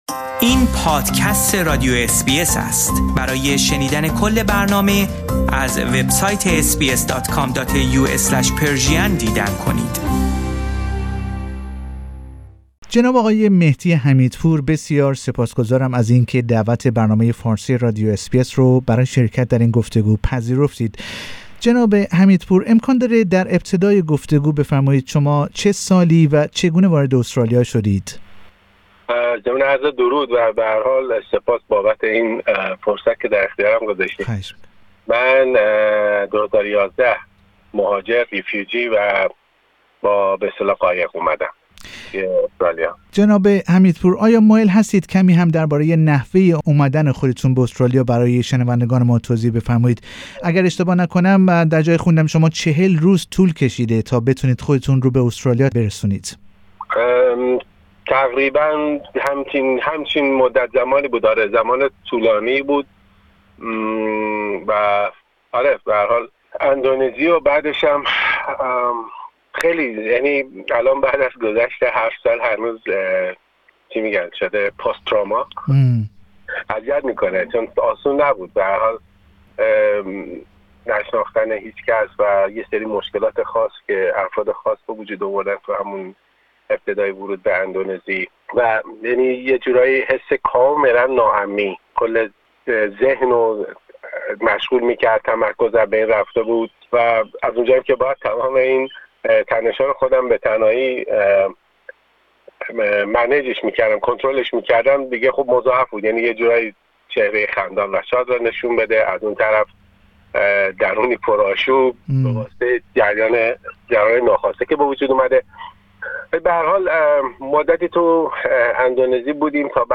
در گفتگو با برنامه فارسی اس بی اس